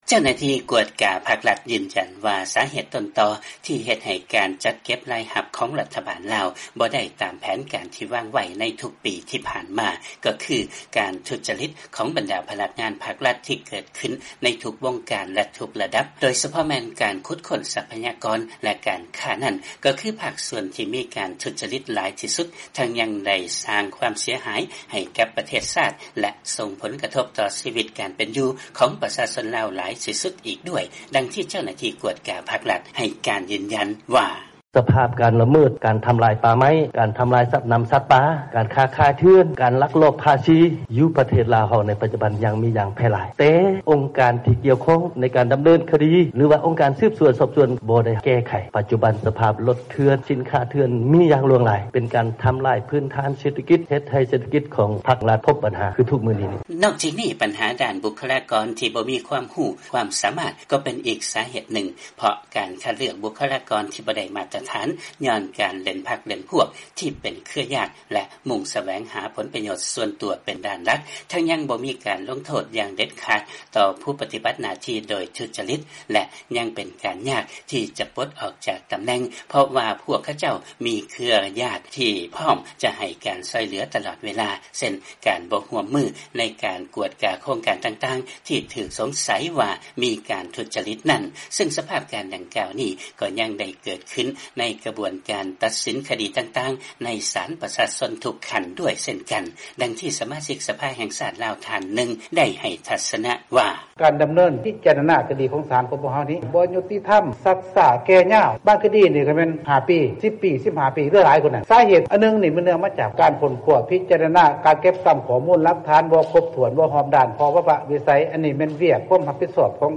ຟັງລາຍງານ ກ່ຽວກັບລັດຖະບານລາວມີພະນັກງານທີ່ປະຕິບັດໜ້າທີ່ໂດຍທຸດຈະລິດຫຼາຍຂຶ້ນ ຫາກແຕ່ບໍ່ມີການລົງໂທດຂັ້ນເດັດຂາດ
by ສຽງອາເມຣິກາ ວີໂອເອລາວ